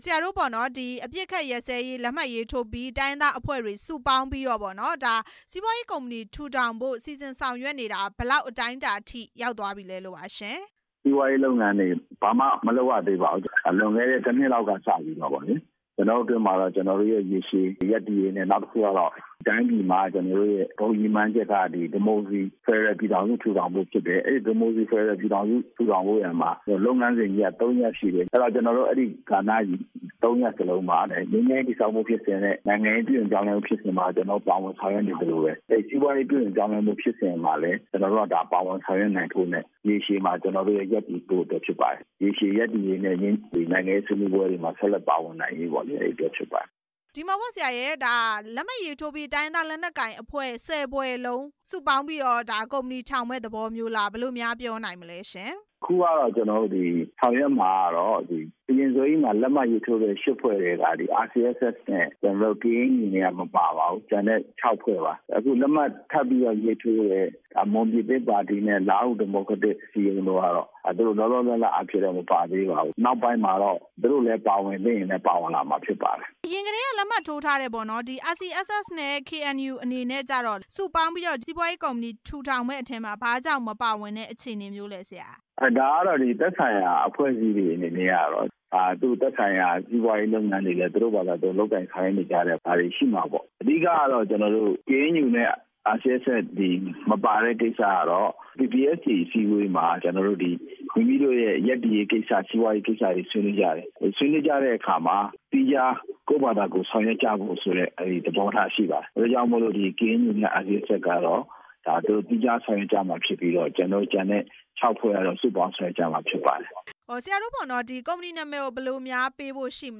ဆက်သွယ်မေးမြန်းထားပါတယ်။